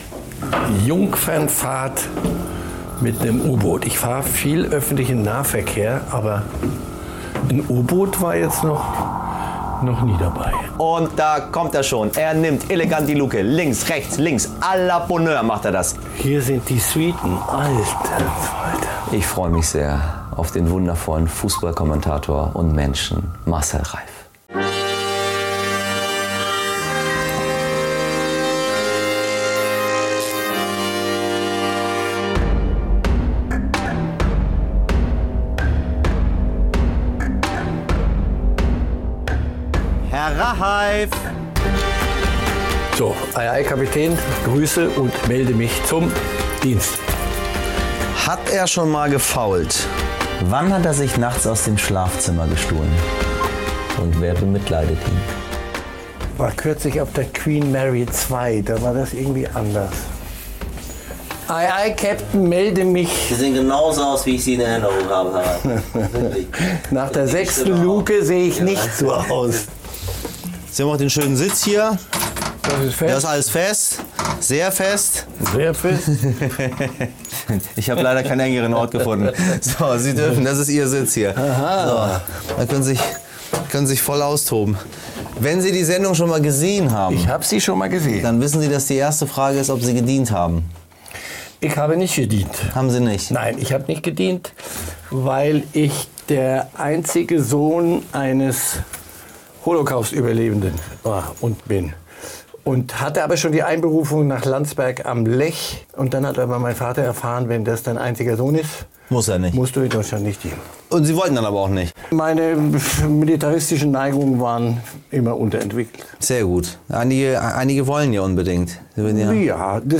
Bei Michel Abdollahi im U-Boot ist der profilierte Fußballkommentator Marcel Reif zu Gast.